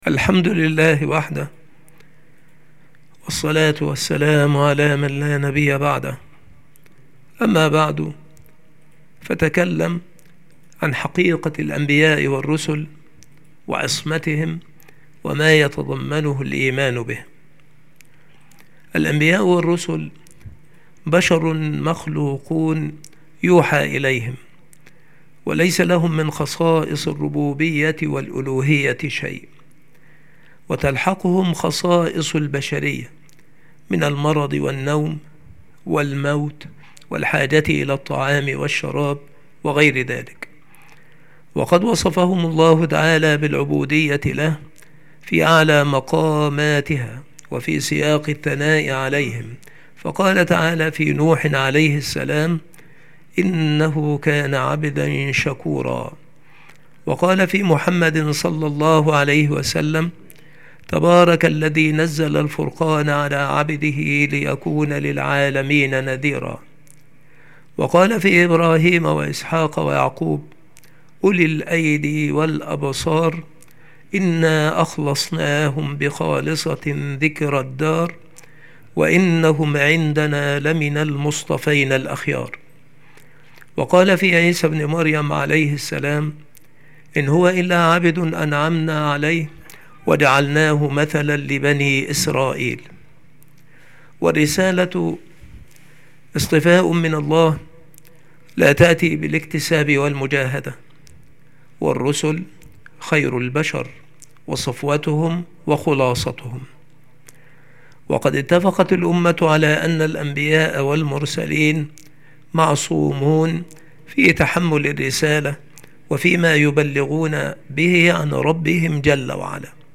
• مكان إلقاء هذه المحاضرة : المكتبة - سبك الأحد - أشمون - محافظة المنوفية - مصر